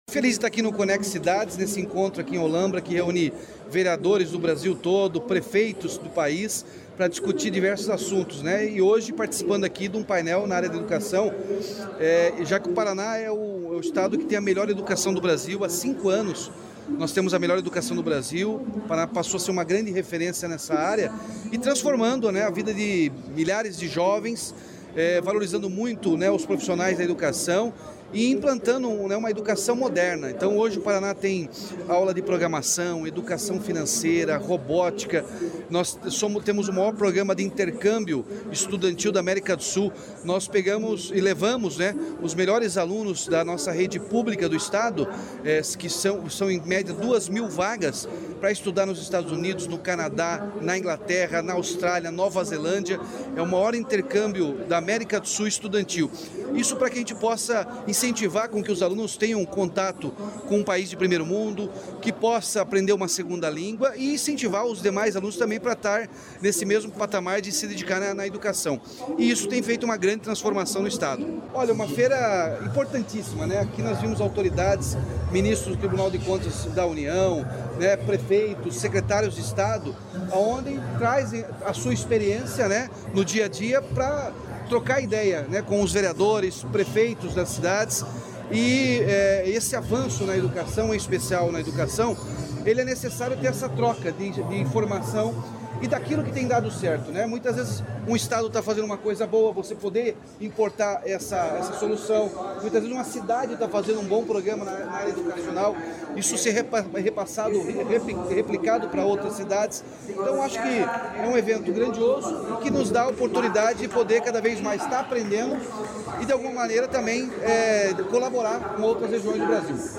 Sonora do governador Ratinho Junior sobre a educação do Paraná durante o Conexidades